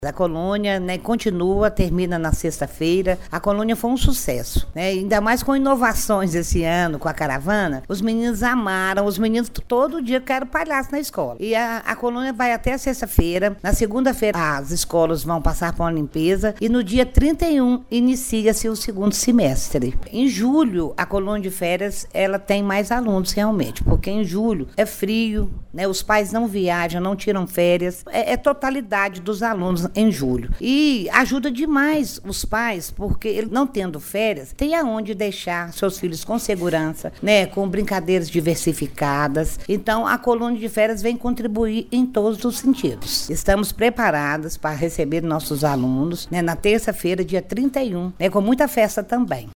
Marluce de Souza Pinto Coelho, secretária municipal de Educação, afirma que as brincadeiras promovidas com as crianças foi um grande sucesso. Ela participou de algumas atividades e faz uma avaliação positiva: